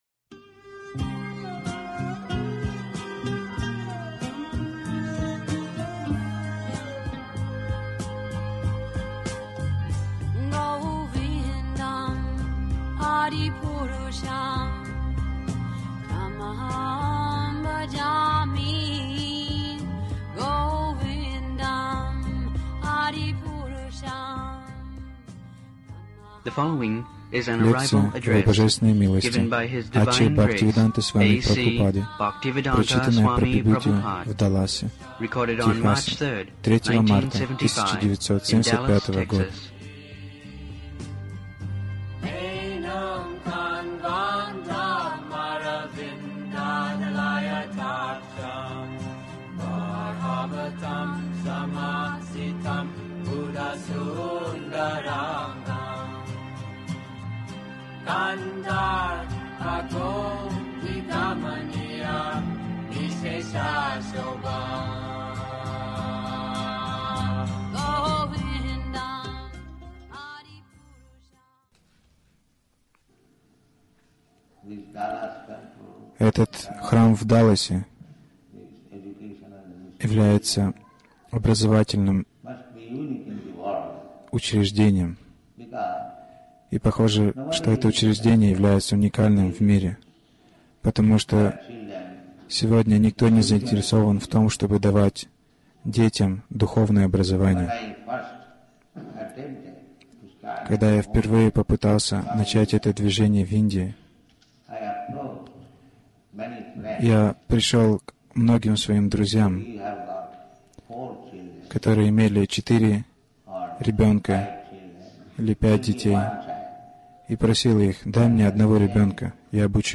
Лекция по прибытию в Даллас